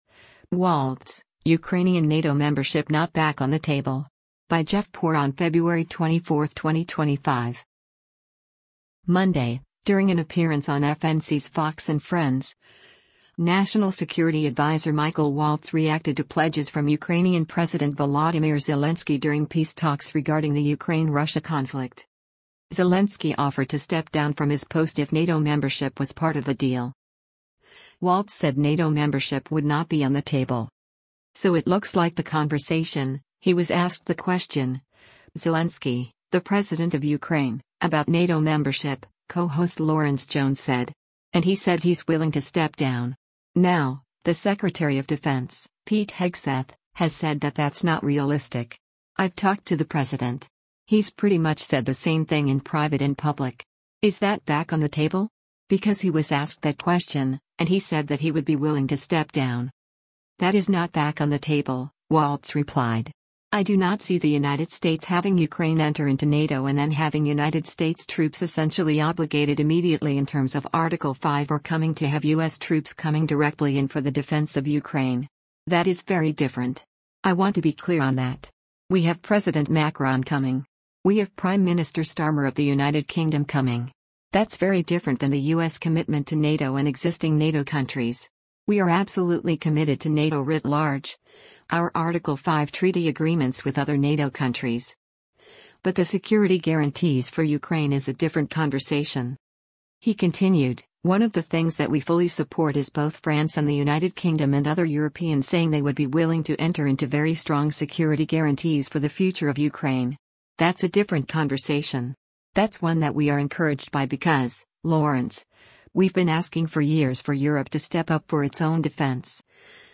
Monday, during an appearance on FNC’s “Fox & Friends,” National Security Advisor Michael Waltz reacted to pledges from Ukrainian President Volodymyr Zelensky during peace talks regarding the Ukraine-Russia conflict.